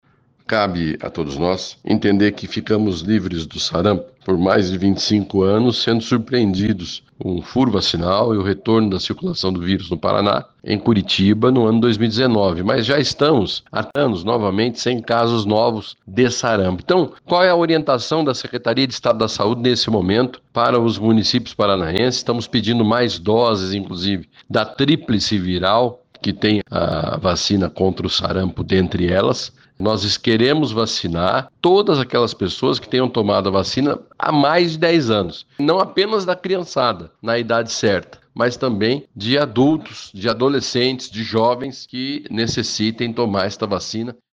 O secretário de Saúde do Paraná, Beto Preto, destacou que a vacinação deve ser feita em adultos e crianças, que não estão com o esquema vacinal completo. O secretário disse que já solicitou mais doses do imunizante ao Ministério da Saúde.